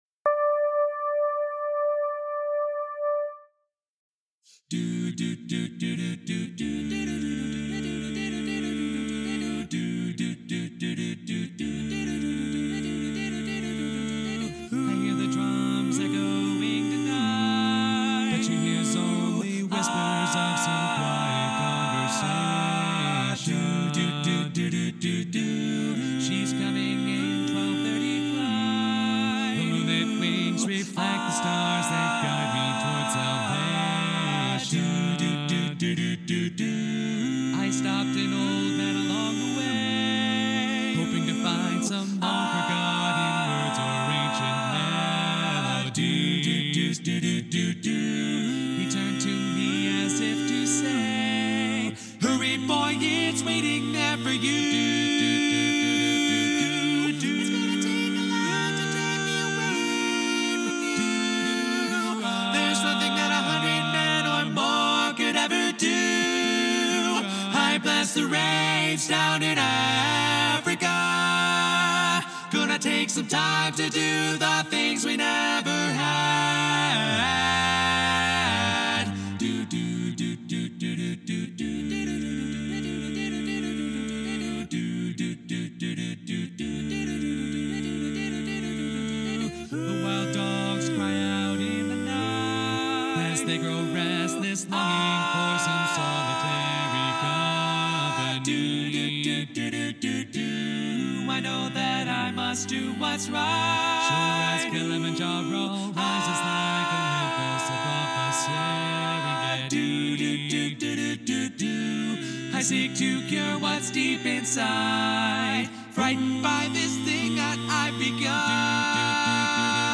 Up-tempo
B♭Major
Bari